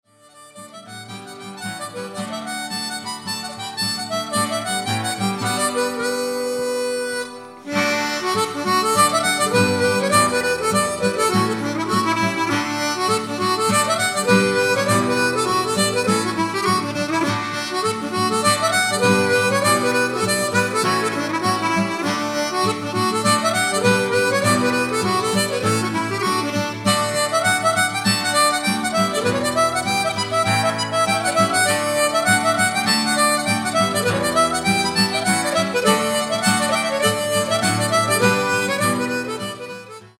jig, reel
• Diatonic harmonicas
Acoustic guitar, bass guitar, fiddle, mandolin, vocals